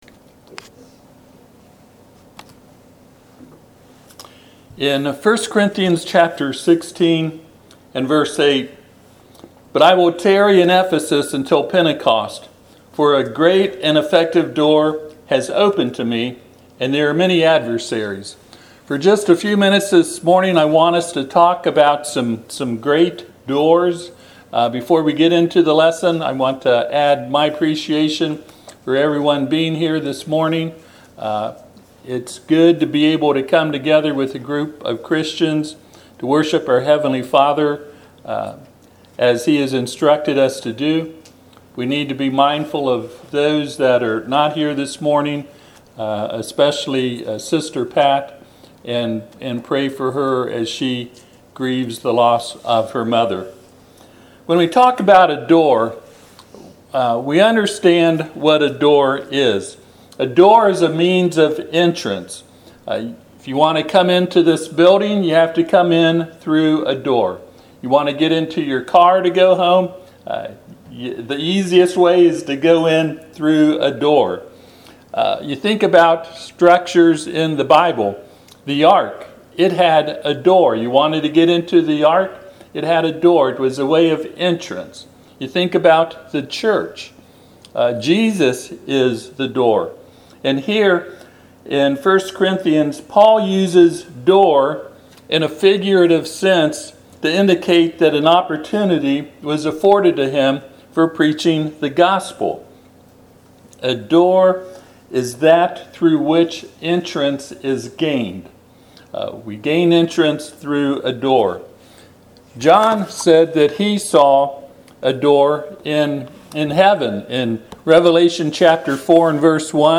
1 Corinthians 16:8-9 Service Type: Sunday AM 1Corinthians 16:8-9 But I will tarry in Ephesus until Pentecost.